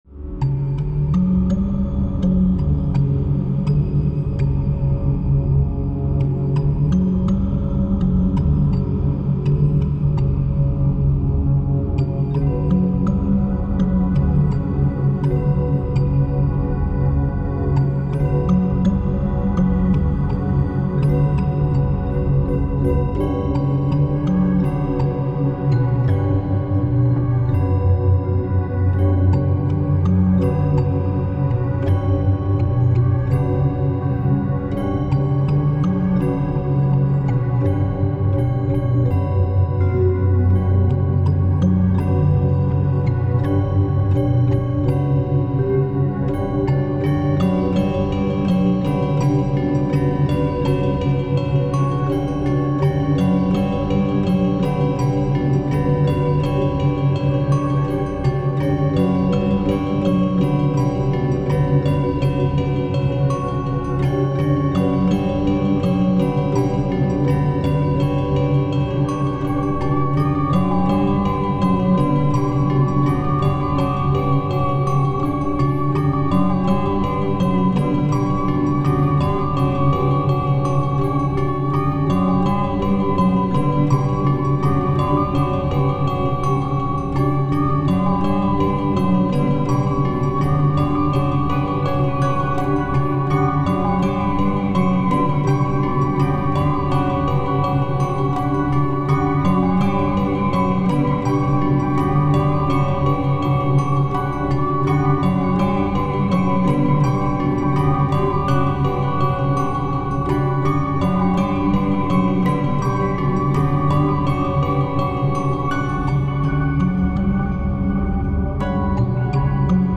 水の底に沈んだ村をイメージして水中楽曲シリーズのサウンドと組み合わせている。